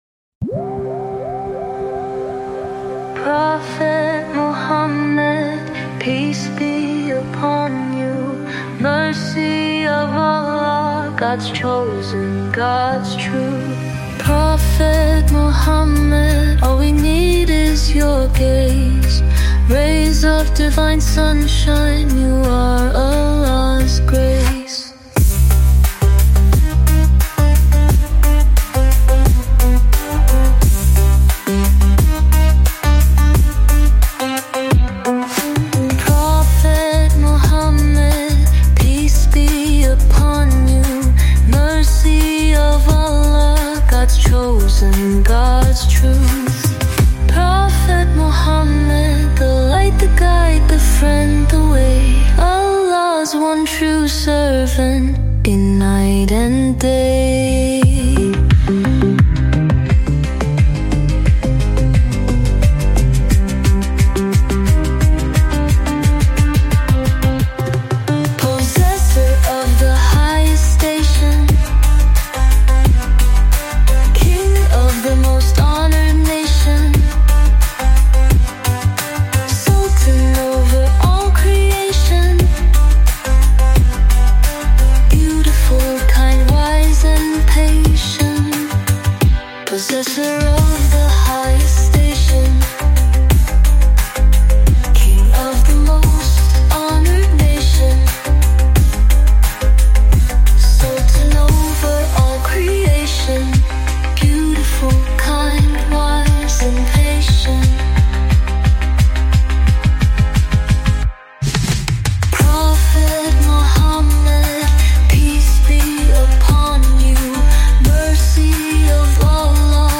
From Live Mawlid 053025